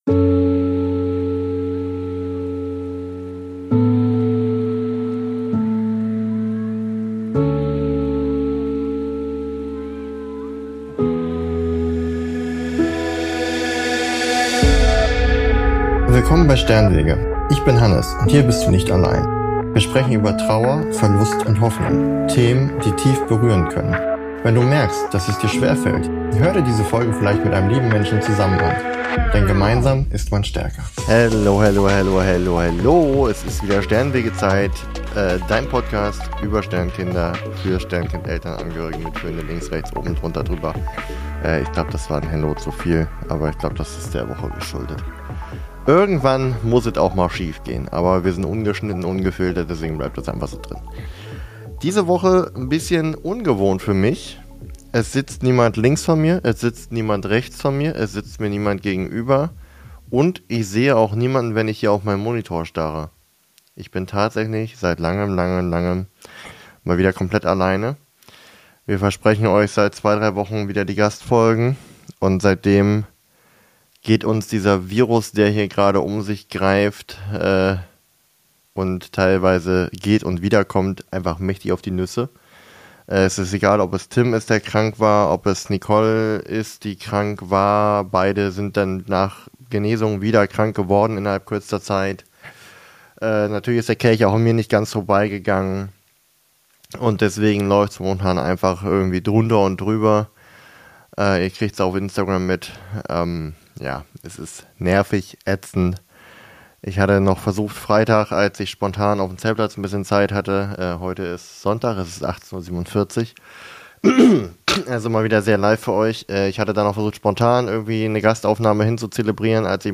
In dieser Solo-Folge von Sternenwege nehme ich Dich mit in einen sehr persönlichen Abend – allein auf dem Zeltplatz, mit vielen Gedanken und noch mehr Gefühlen.